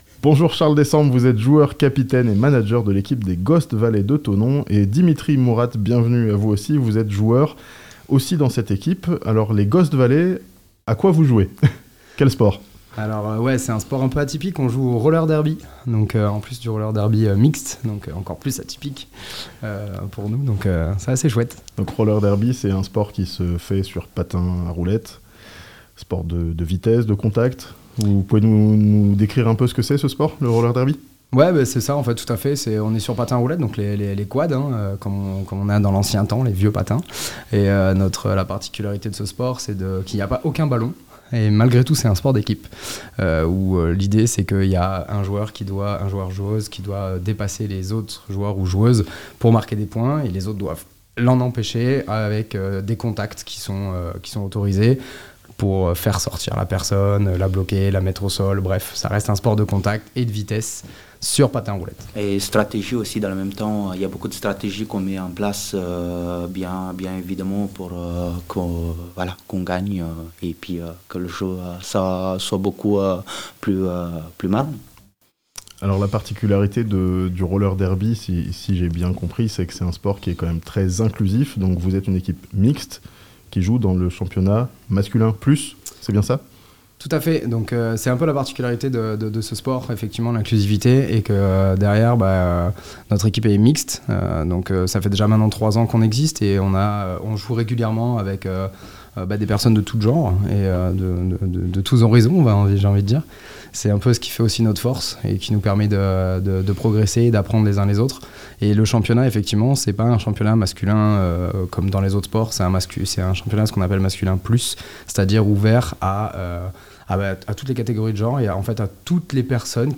Champions de Nationale 1, les "Ghost Valley" de Thonon intègrent l'élite du roller derby (interview)